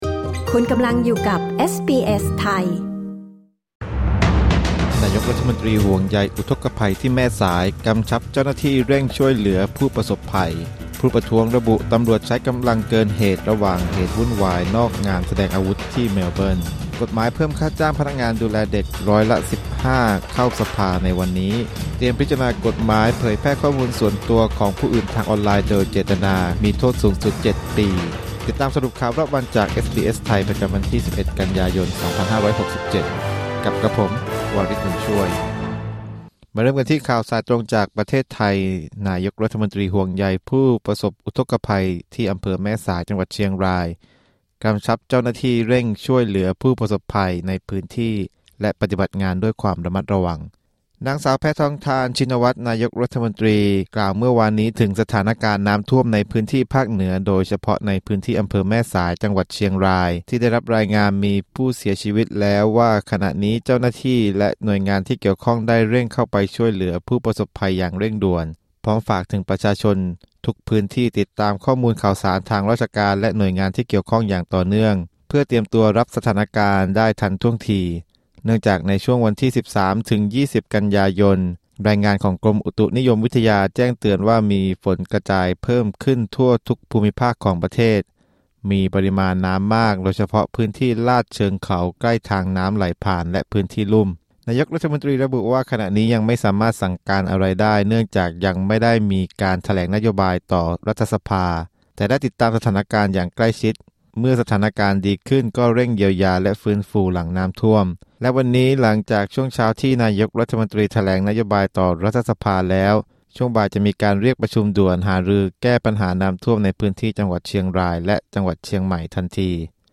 สรุปข่าวรอบวัน 12 กันยายน 2567